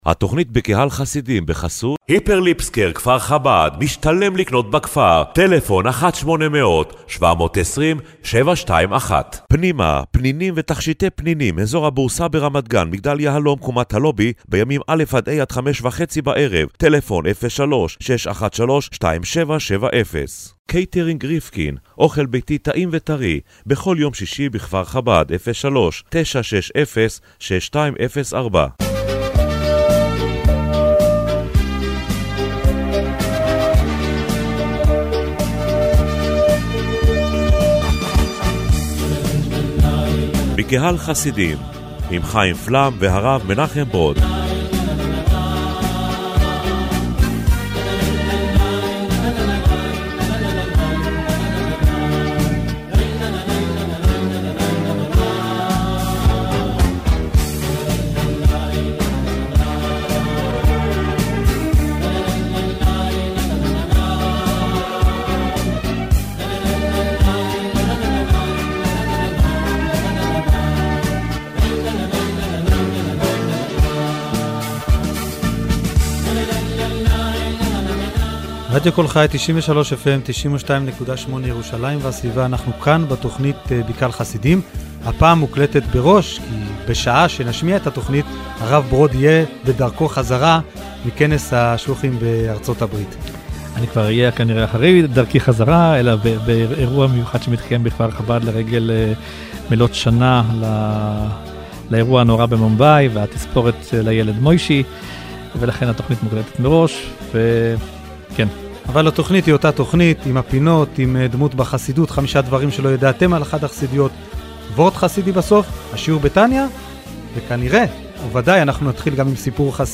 במרכז תכנית הרדיו השבועית 'בקהל חסידים' בשבוע שעבר עמדה ההתייחסות החסידית לנושא ההתבוננות, וחשיבותה הגדולה לקליטת ענייני חסידות בעומק הנפש. בפינה 'הסיפור החסידי ומה שמאחוריו' הובא סיפור על חייט קל-דעת שהציל נפש ובזכות זה חזר בתשובה שלמה וזכה להוליד בן צדיק.